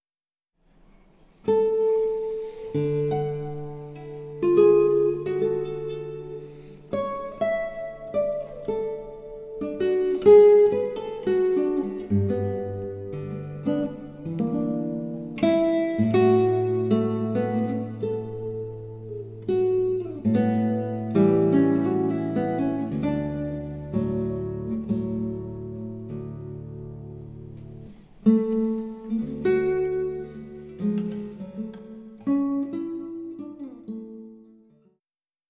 für Gitarre
guitar